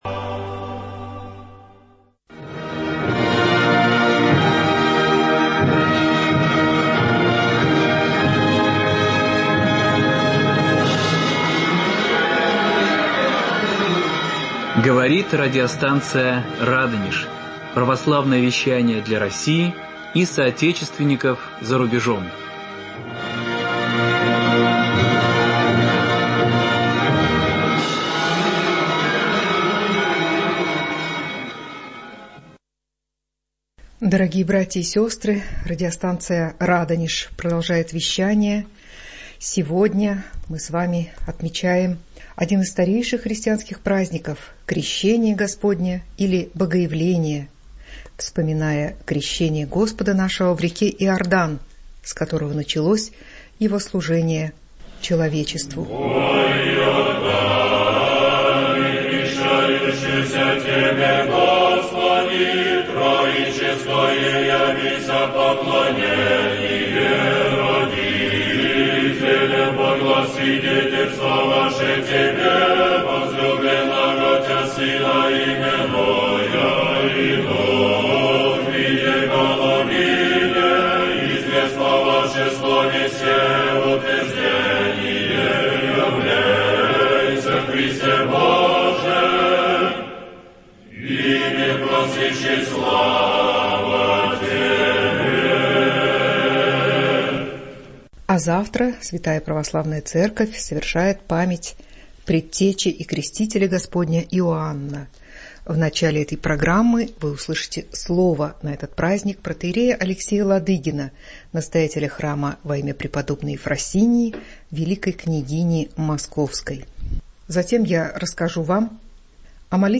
Репортаж со встречи с депутатом Госдумы в парке Торфянка.